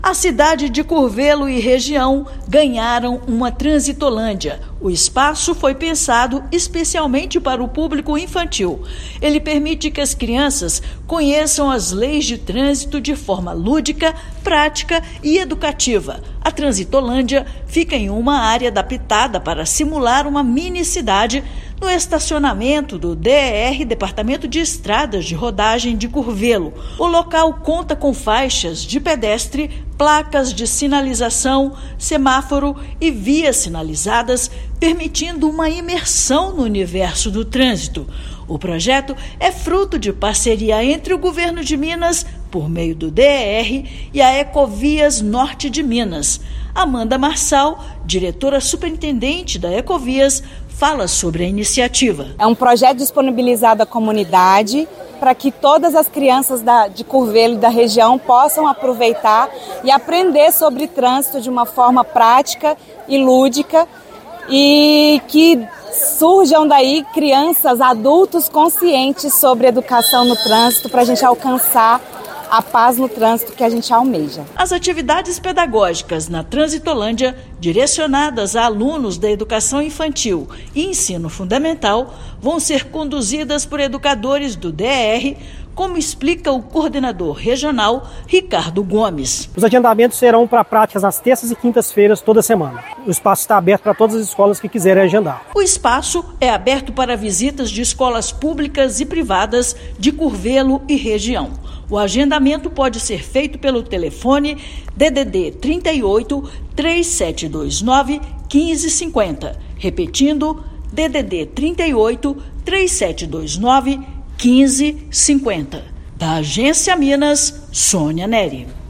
Instalado em Curvelo, espaço vai atender mais de 21 mil crianças do município e da região anualmente, com objetivo de formar condutores mais conscientes no futuro. Ouça matéria de rádio.